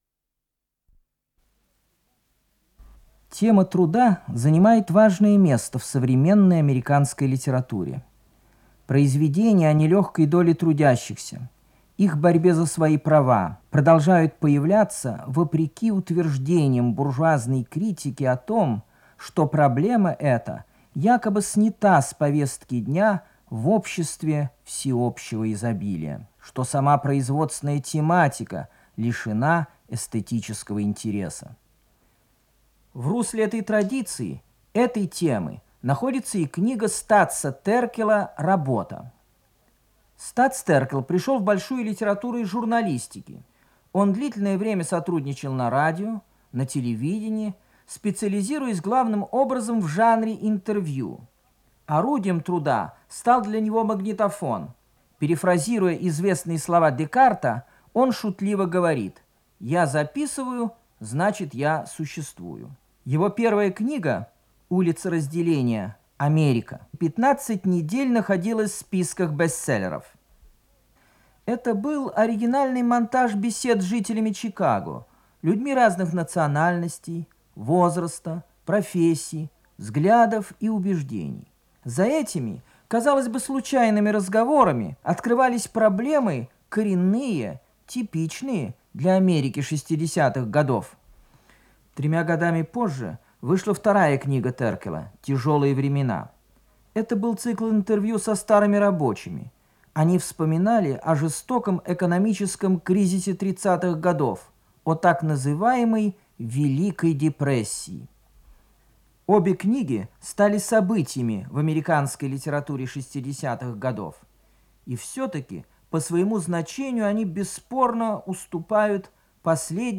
Вступительное слово
Страницы романа